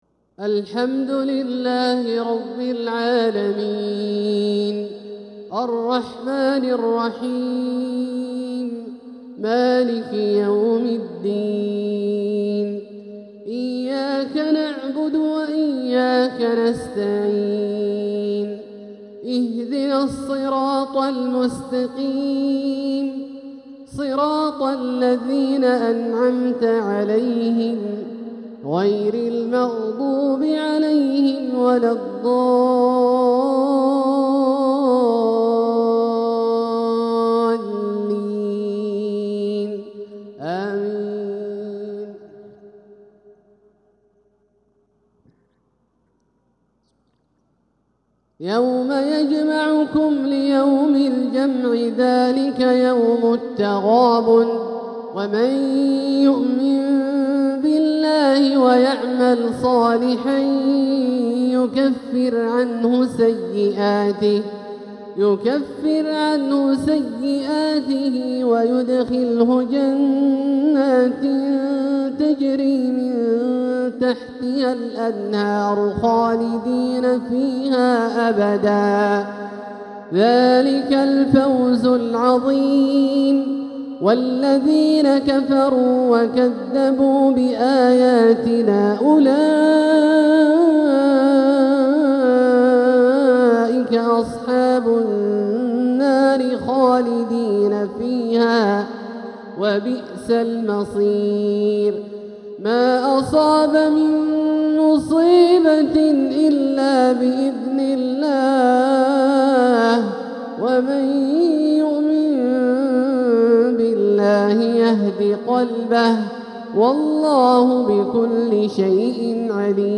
تلاوة لخواتيم سورة التغابن 9-18 | عشاء الأربعاء 12 صفر 1447هـ > ١٤٤٧هـ > الفروض - تلاوات عبدالله الجهني